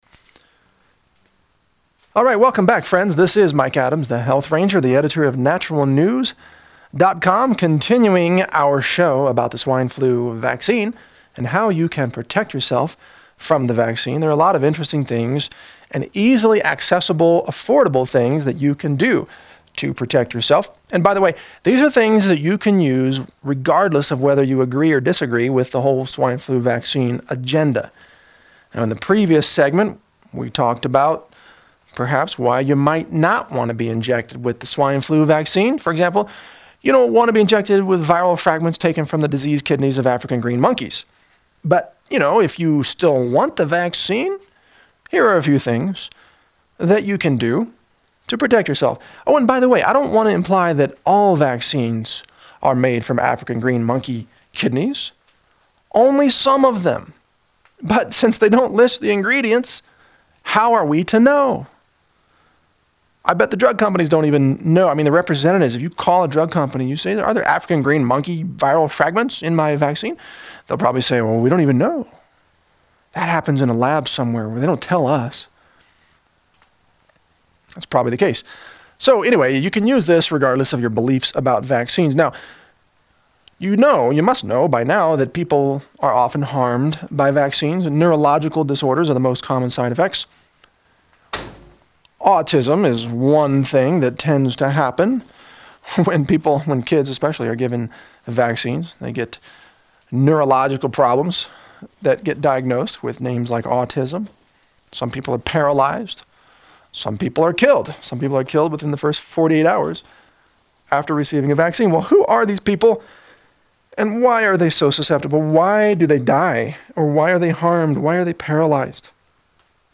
Radio show part two - How to protect yourself from the swine flu (MP3)